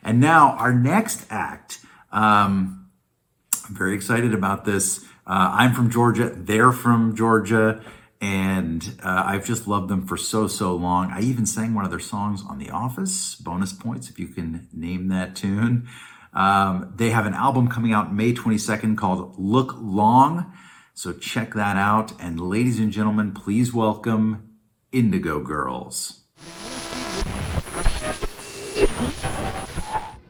(captured from the youtube broadcast)